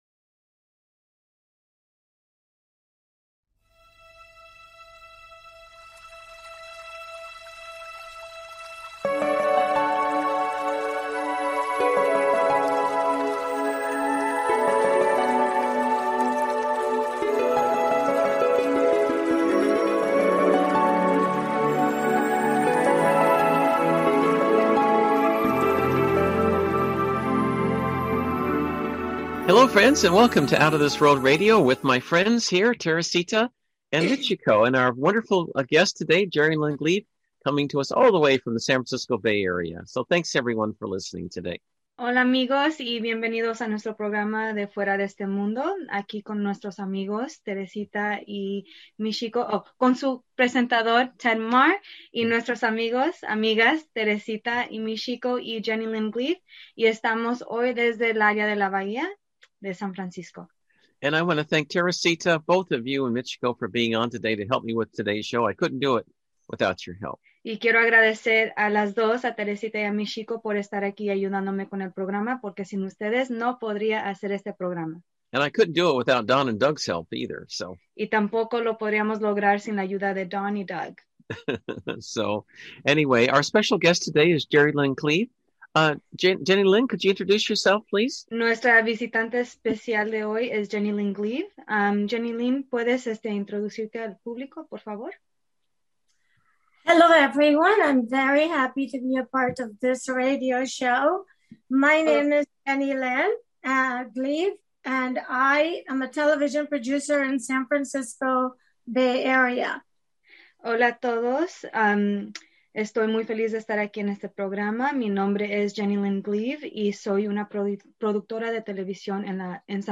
Talk Show Episode, Audio Podcast, Out of This World Radio - Spanish and Guests